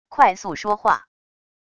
快速说话wav音频